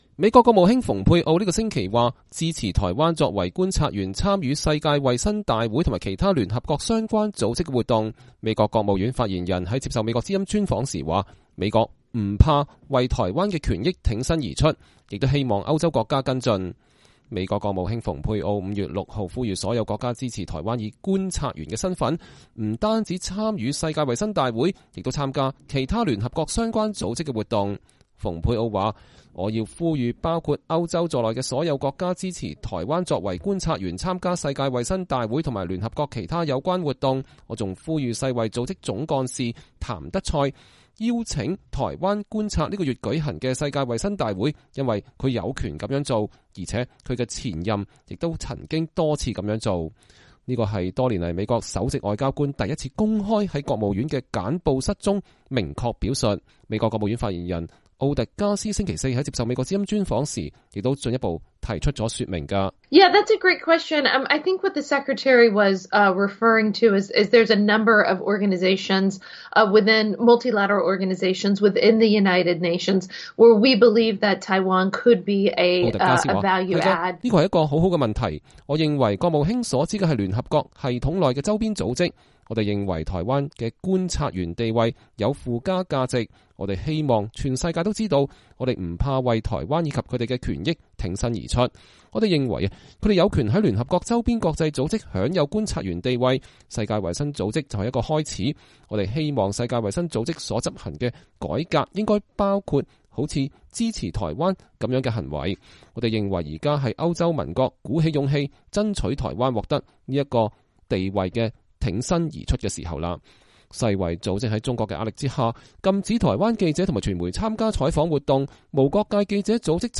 專訪美國國務院發言人：美支持台灣成為聯合國週邊組織觀察員
美國國務院發言人摩根·奧特加斯星期四在接受美國之音專訪的時候進一步提出說明。